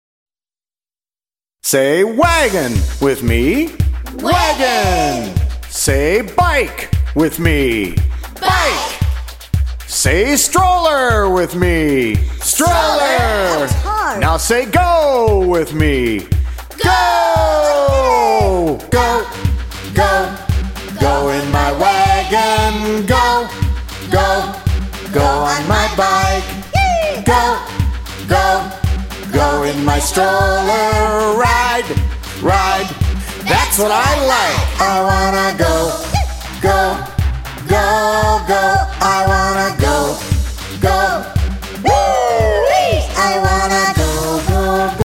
-Kids and adults singing together and taking verbal turns